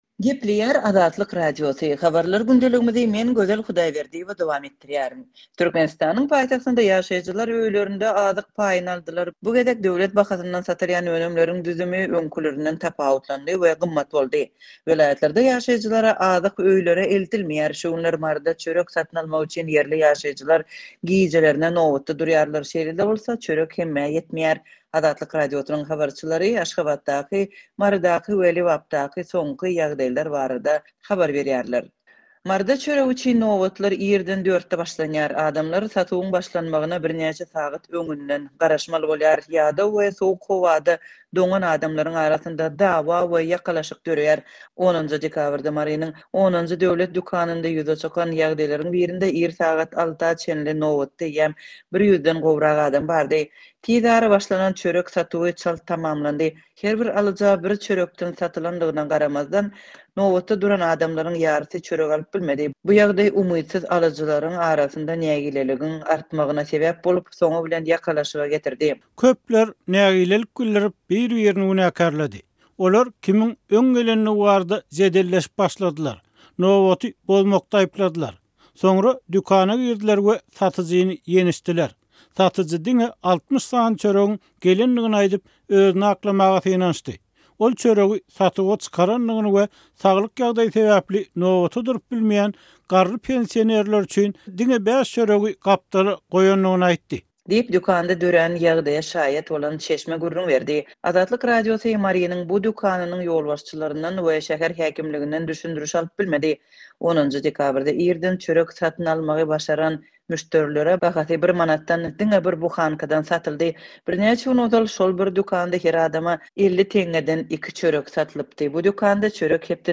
Şu günler Maryda çörek satyn almak üçin ýerli ýaşaýjylar gijelerine nobatda durýarlar, şeýle-de bolsa, çörek hemmä ýetmeýär. Azatlyk Radiosynyň habarçylary Aşgabatdaky, Marydaky we Lebapdaky soňky ýagdaýlar barada habar berdiler.